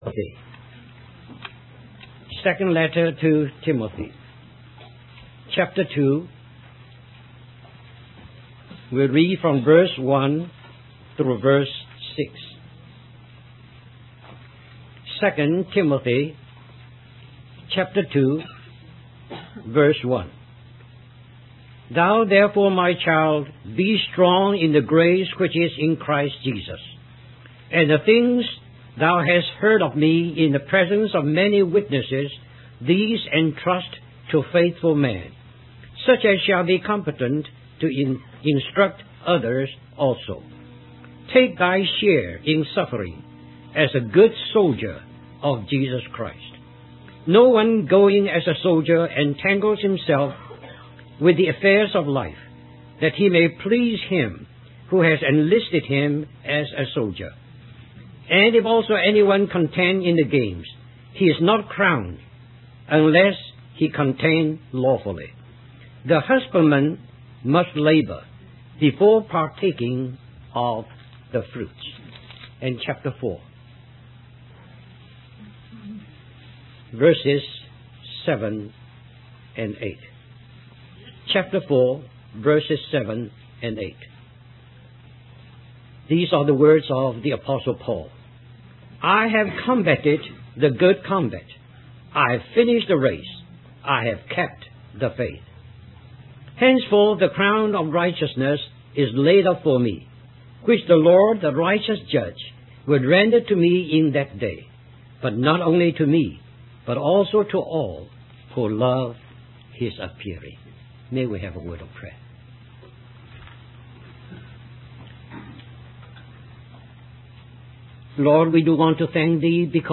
In this sermon, the speaker emphasizes the importance of Christians understanding that they are called to fight the good fight of faith. He uses the example of the children of Israel being delivered from slavery in Egypt and being given their own land as a reminder that God has called believers to a higher purpose.